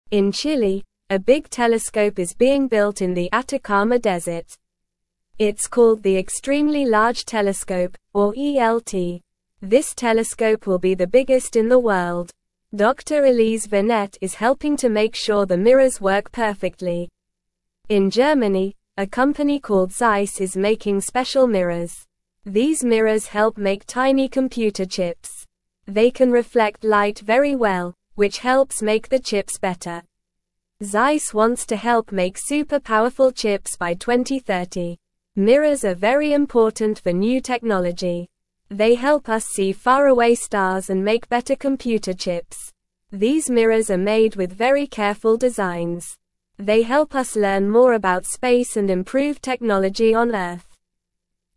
Normal
English-Newsroom-Lower-Intermediate-NORMAL-Reading-Building-a-Big-Telescope-and-Making-Smooth-Mirrors.mp3